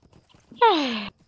Play, download and share sigh of the ages original sound button!!!!
dols-sigh.mp3